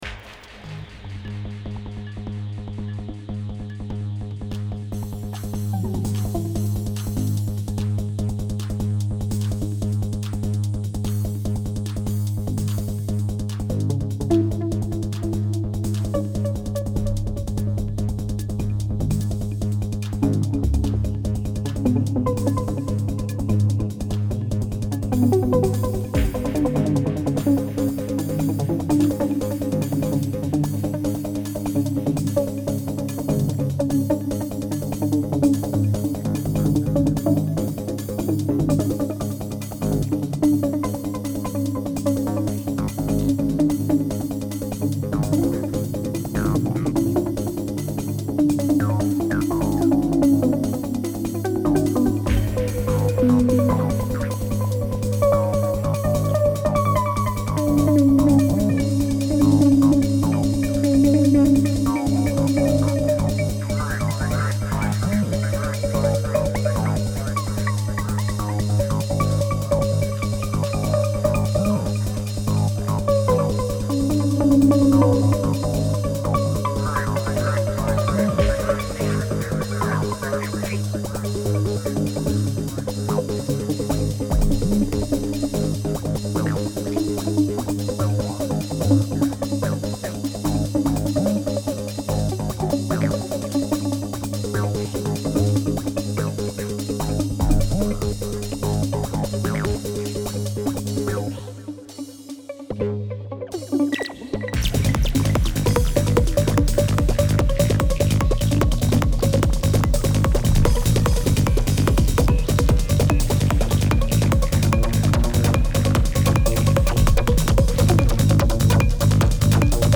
Tb-303 ADM ABL3X System-100 trance